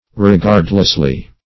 [1913 Webster] -- Re*gard"less*ly, adv.